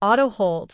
audio_auto_hold.wav